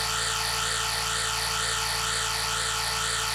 sci-fi_forcefield_hum_loop_01.wav